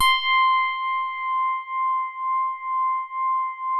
FINE HARD C5.wav